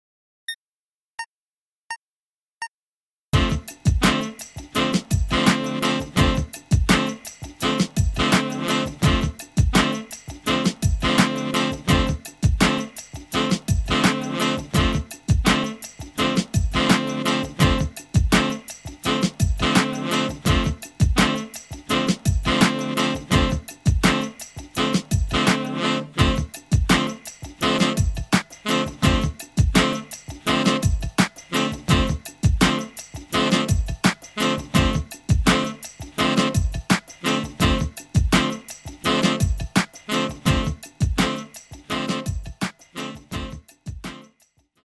Saxophone Solo